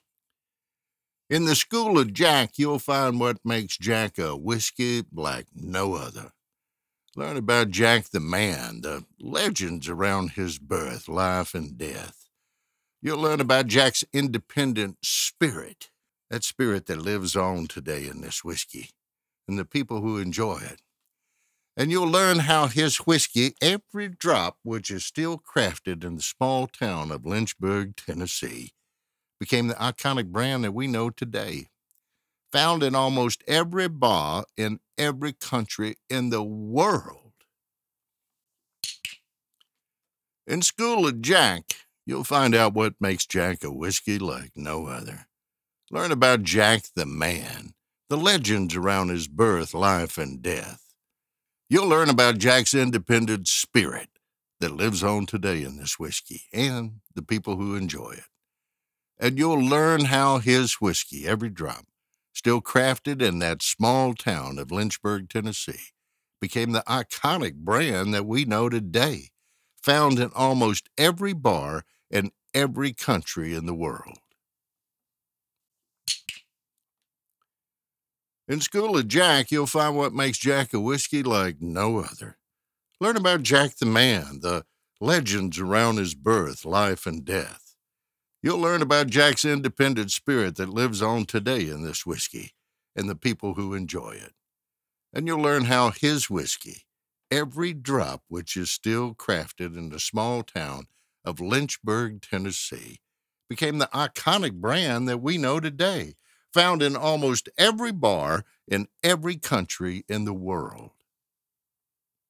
Professional Voice Over Artist
English Neutral, English - Southern US
Senior